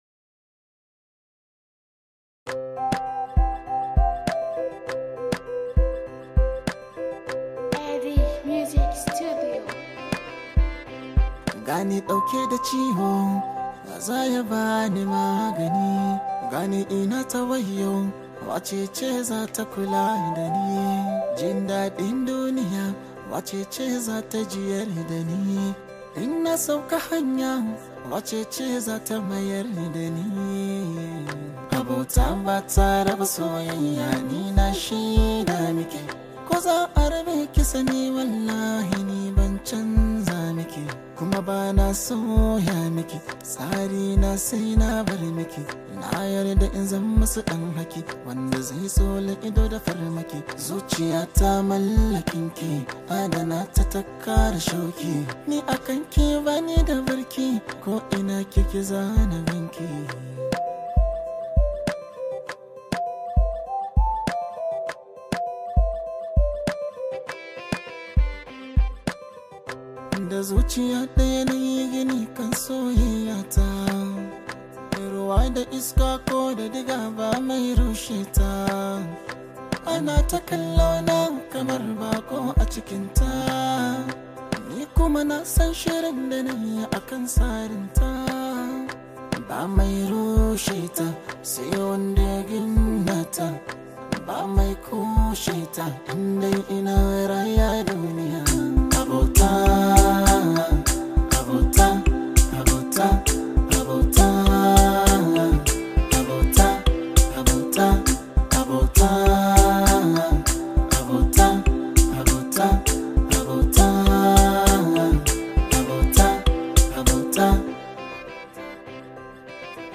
Nigerian singer